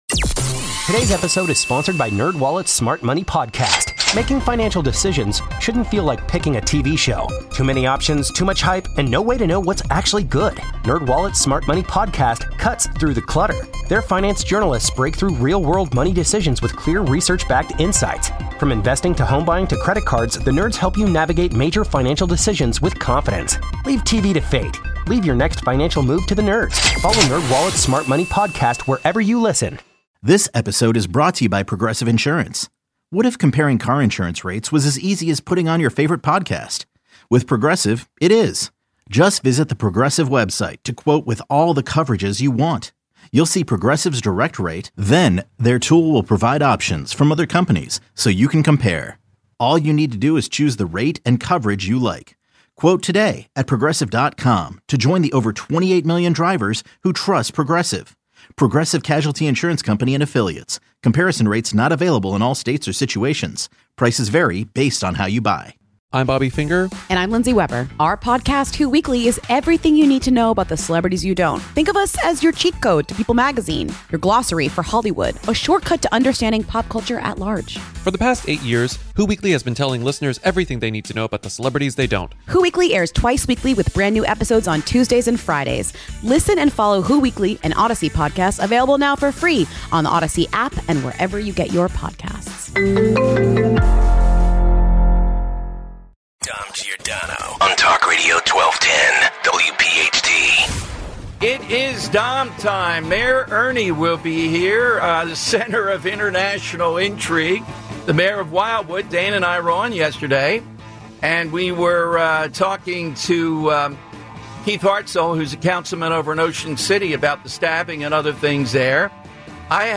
In his press conference, DeNiro takes on a heckler who calls out the January 6th officers alongside DeNiro ‘traitors,’ which causes some fireworks.
Yesterday, after needing to issue a State of Emergency over the weekend, Ernie put out a news release that called attention to an edict set forth statewide by Governor Phil Murphy that largely ties the hands of police when dealing with juveniles on the boardwalk imbibing in marijuana and alcohol use. Mayor Troiano joins to delve into the situation, explaining what he hopes will be done to allow his law enforcement officers to keep these teen mobs from developing.